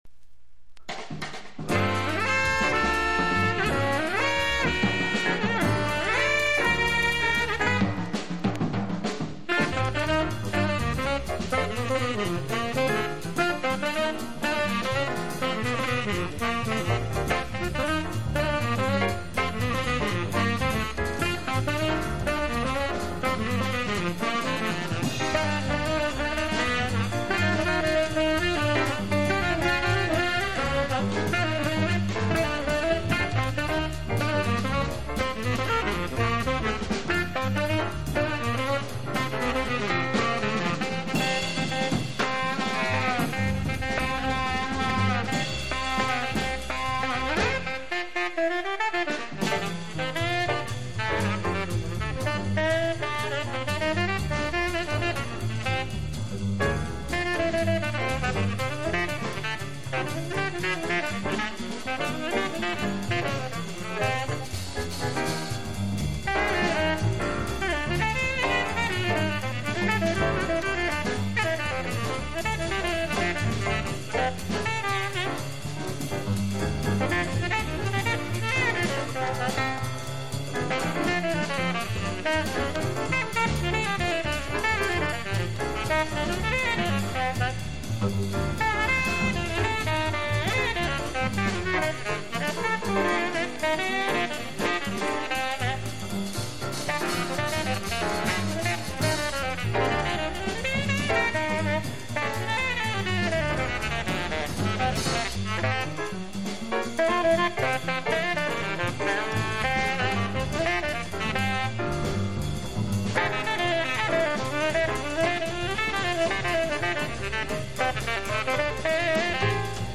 （スレありますが音に影響なし）…